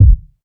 kick 27.wav